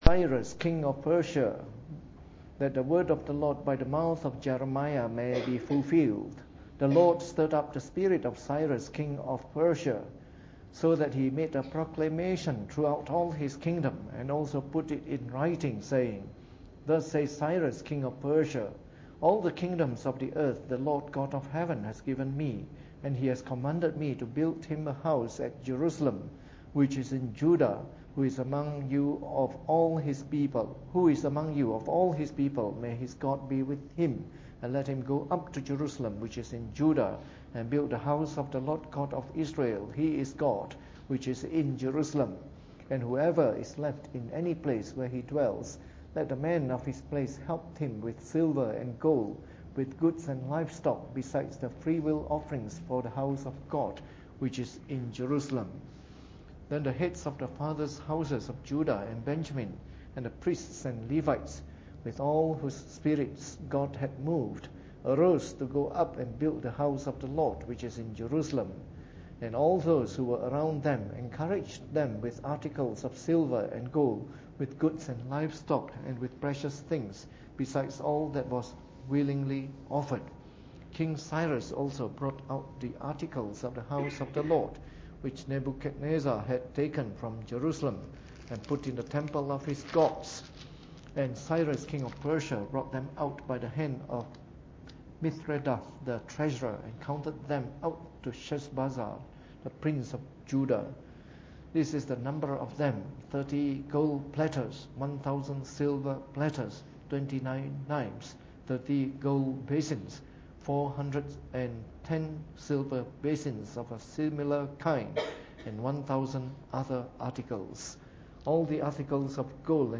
Preached on the 15th of January 2014 during the Bible Study, from our new series of talks on the Book of Ezra.